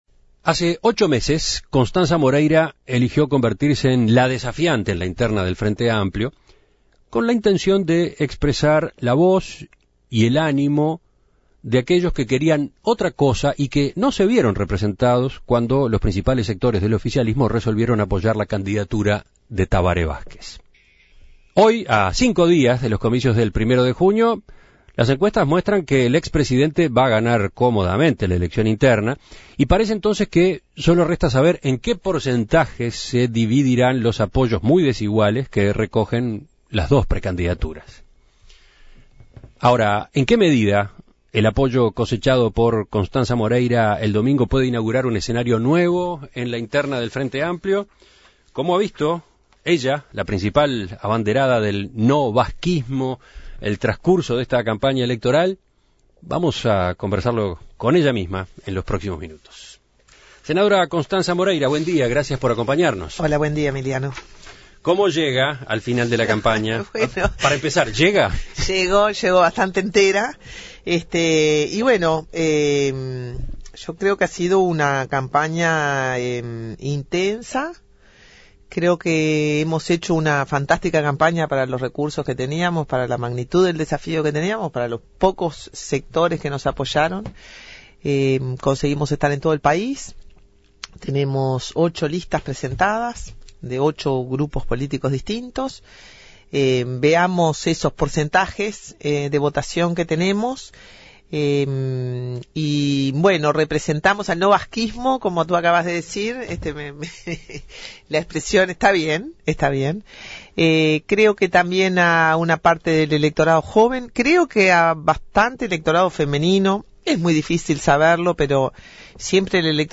Siguiendo con este ciclo de entrevistas, En Perspectiva dialogó con la precandidata Moreira. La senadora aseguró que no sabría en qué lugar de la política estaría de no haber generado este nuevo lugar que, según ella, no pertenece ni al "vazquismo" ni a los sectores mayoritarios del Frente Amplio.